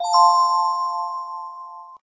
bright_bell_chime_shorter.ogg